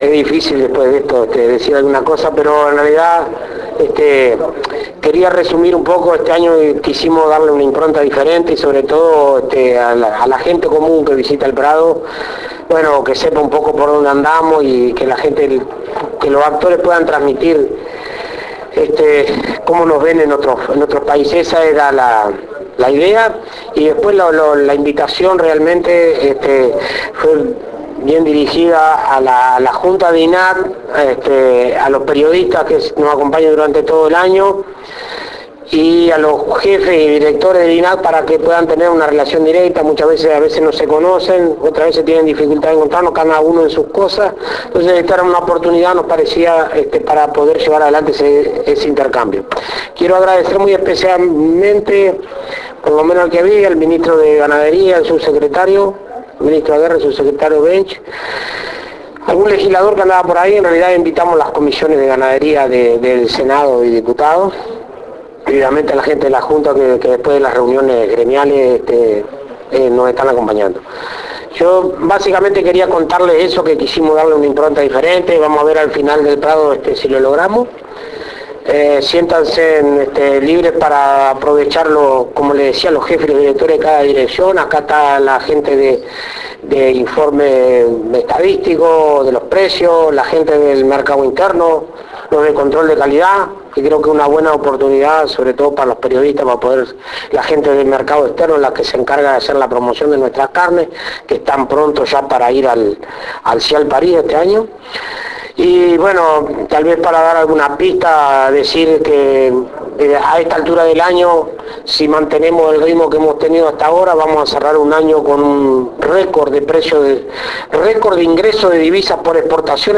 Se realizó la presentación de la propuesta de INAC en Expo Prado 2012.
Presidente de INAC Dr. Alfredo Fratti. mp3.
Entrevista al Dr. Fratti. mp3.